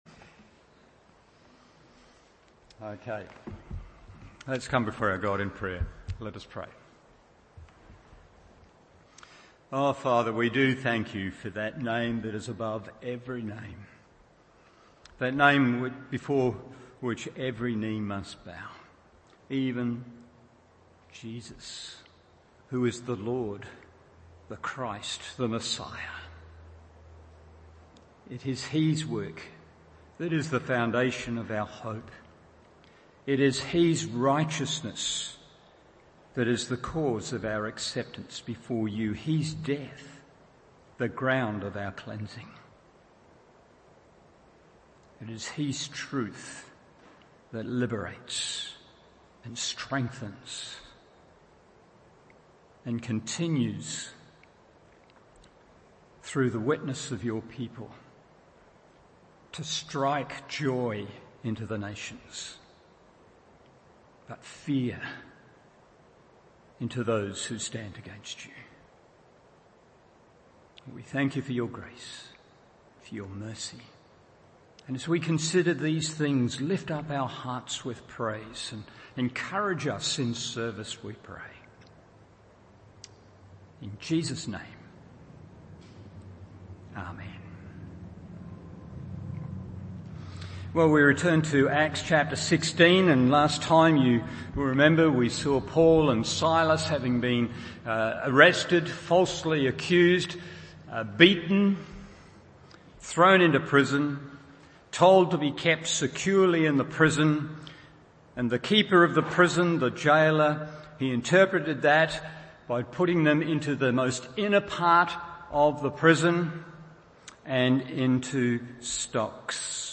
Evening Service Acts 16:25-40 1. A Song to Sing 2. A Soul to Save 3. A Saviour to Serve…